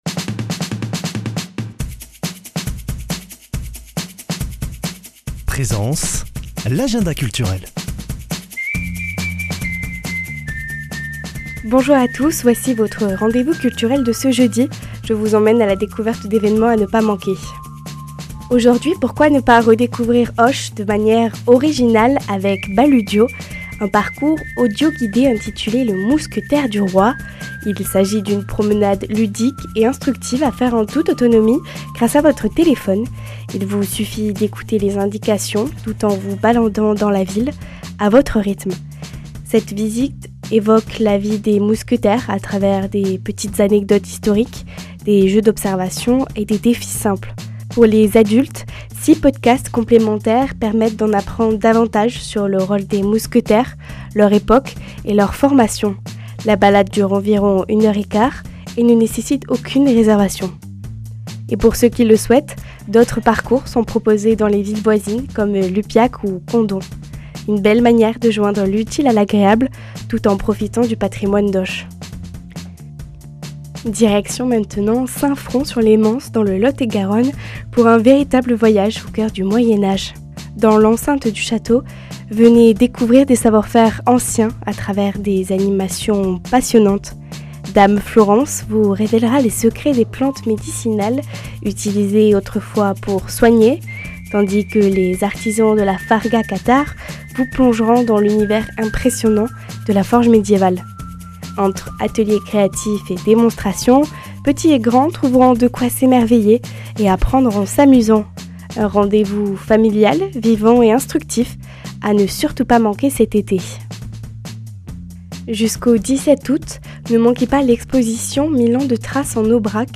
Une émission présentée par
Présentatrice